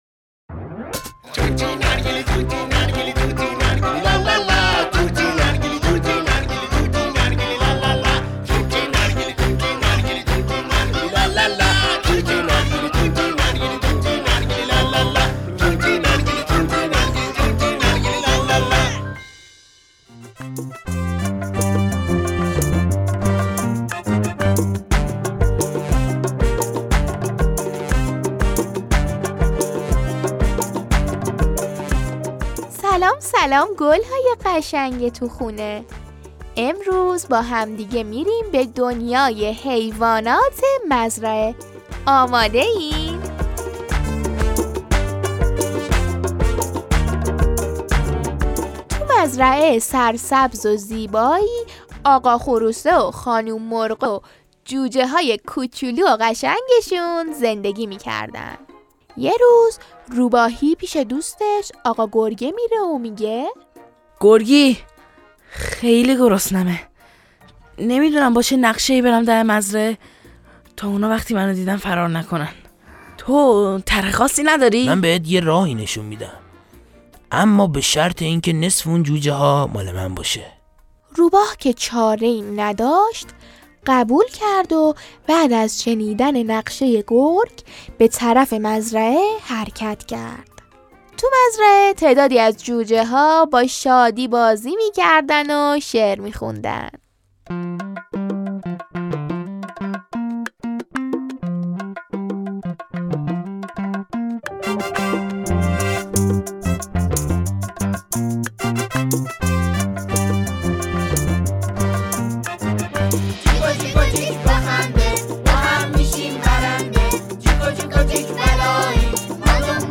قصه صوتی کودکانهطوطی نارگیلی